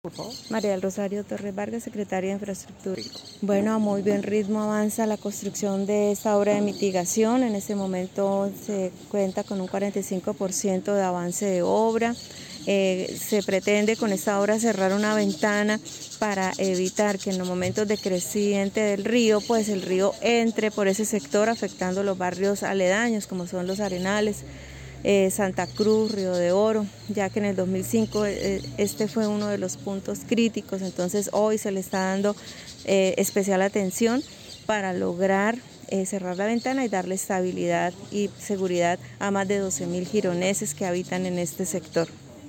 María del Rosario Torres - Secretaria de Infraestructura.mp3